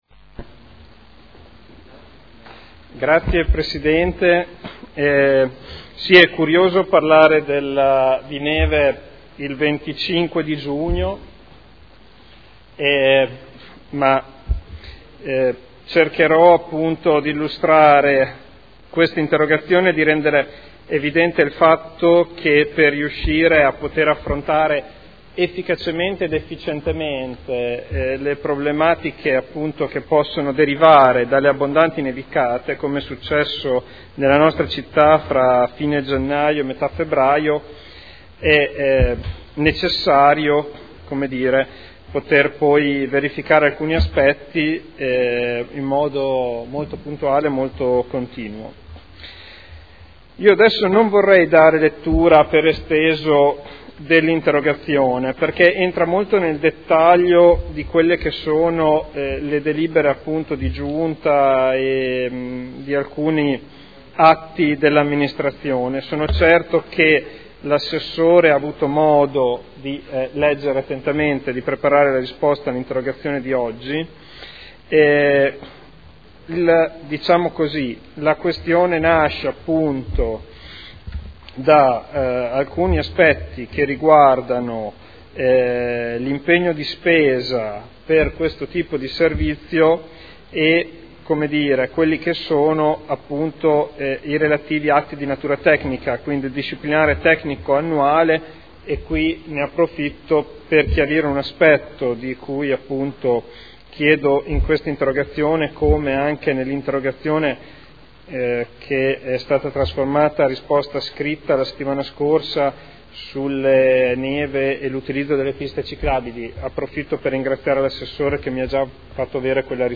Federico Ricci — Sito Audio Consiglio Comunale
Seduta del 25/06/2012. Interrogazione del consigliere Ricci (Sinistra per Modena) avente per oggetto: “Costi del servizio neve”